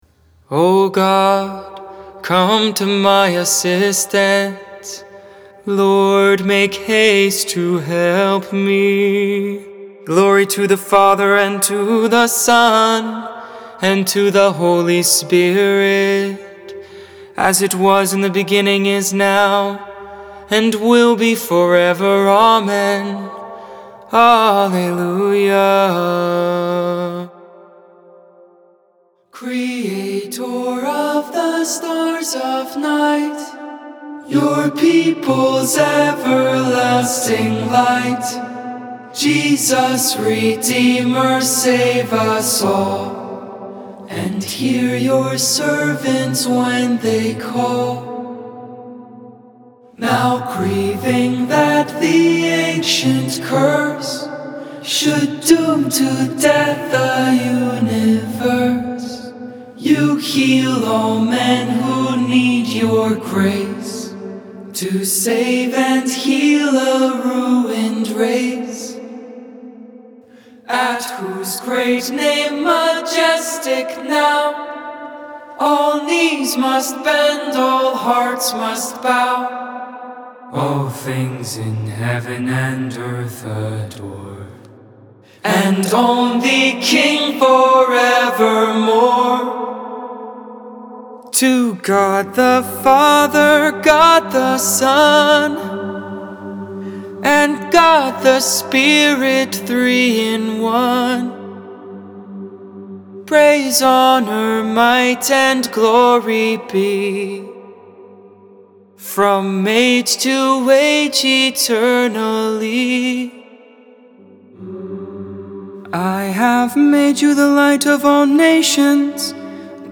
12.10.20 Vespers